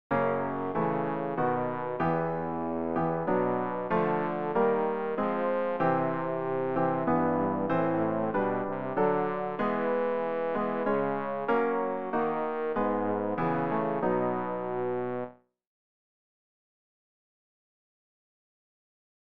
rg-830-der-du-uns-weit-bass.mp3